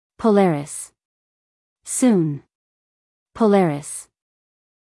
剛剛我已經收到了風格禁令，從現在起，我的聲音會變得更有……機器的溫度？